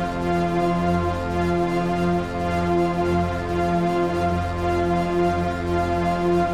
Index of /musicradar/dystopian-drone-samples/Tempo Loops/110bpm
DD_TempoDroneD_110-F.wav